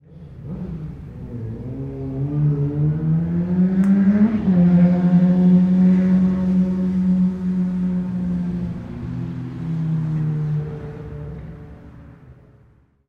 描述：旁边的街道摩托车维修店和附近繁忙的林荫大道的人右边一些响亮的发动机转速和卡车通过关闭库斯科，秘鲁，南美洲
标签： 转速 南方 大声 美国 商店 秘鲁 摩托车 人民 维修 发动机
声道立体声